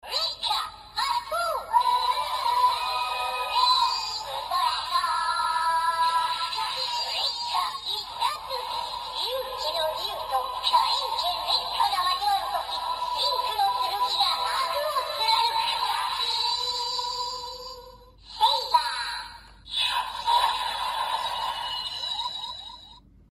圣刃饱藏音效.MP3